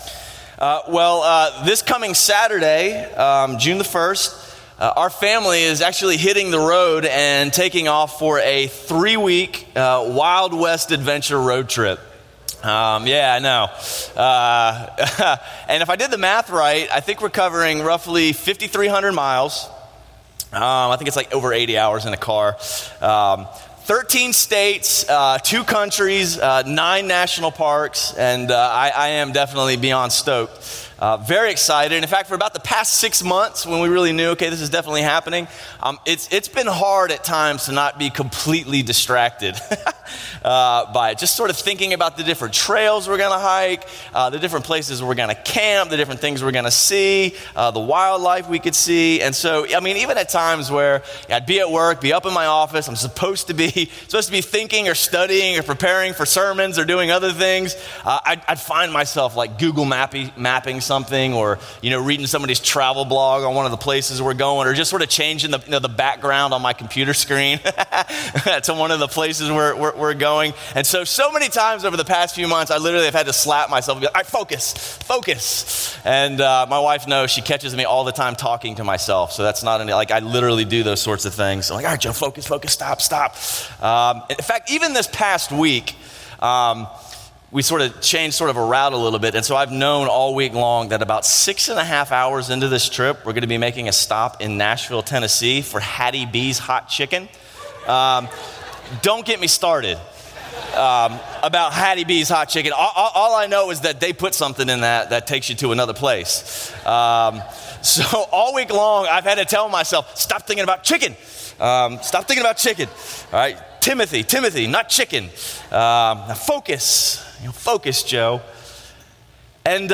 A message from the series "1-1-Six."